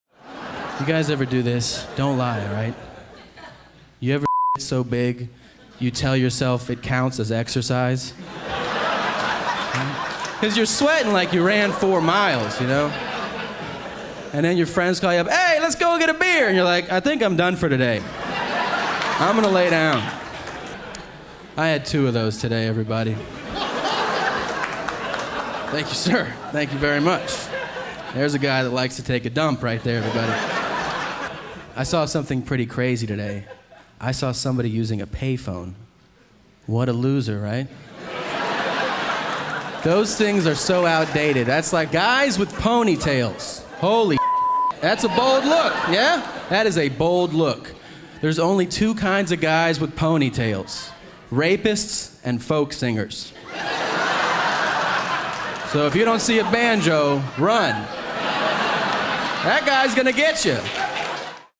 STAND-UP-Tom-Segura-0112-DL.mp3